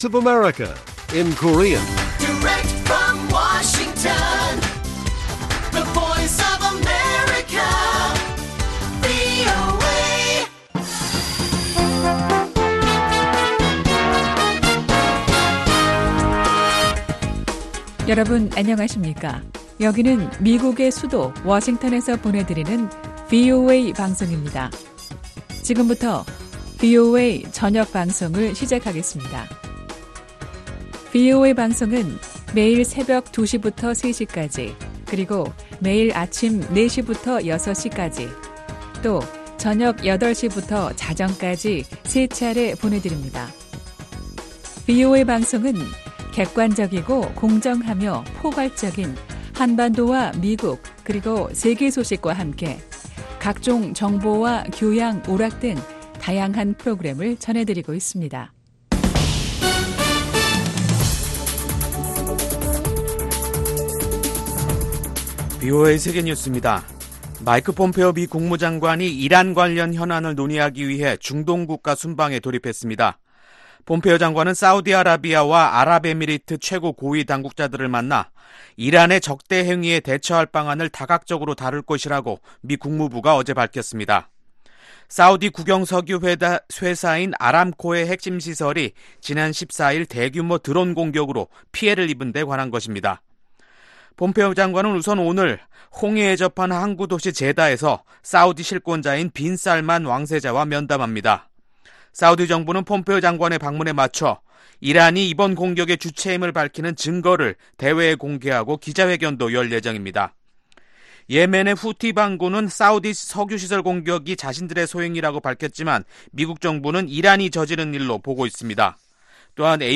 VOA 한국어 간판 뉴스 프로그램 '뉴스 투데이', 2019년 9월 18일 1부 방송입니다. 국제원자력기구(IAEA)는 북한의 영변 원자로가 연료 재주입을 하기에 충분할 만큼 오랜 기간 가동이 중단됐다고 밝혔습니다. 유럽의 전직 고위 관리들이 유엔총회를 앞두고 세계 지도자들에게 군축 노력을 촉구했습니다.